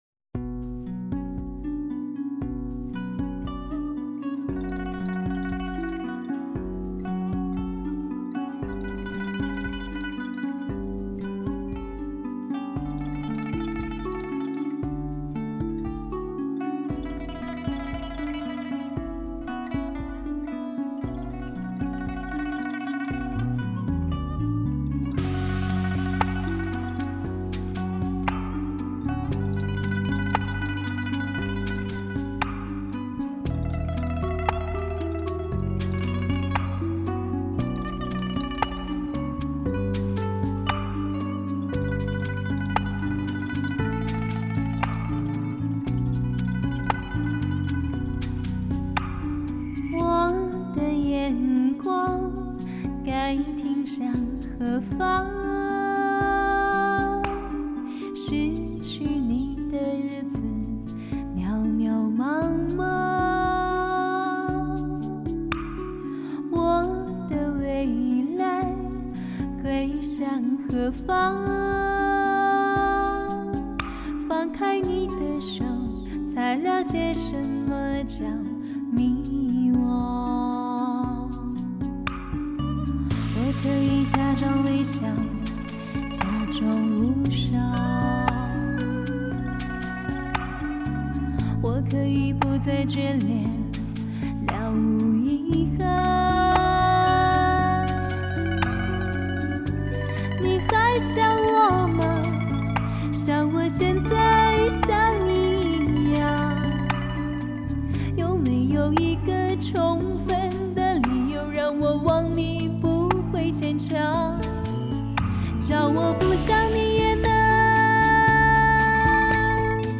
世界顶级录音设备全面满足听觉享受
天籁女声无法抗拒
而配乐中箫，琵琶，二胡，笛子，扬琴，木吉他的编排演奏，
让人心碎的琵琶曲，旋律优美纯粹，轻柔的女声，娓娓道来……